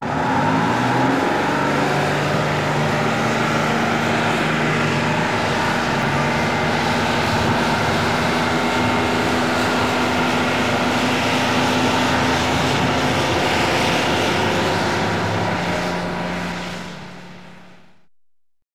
Автобус разгоняется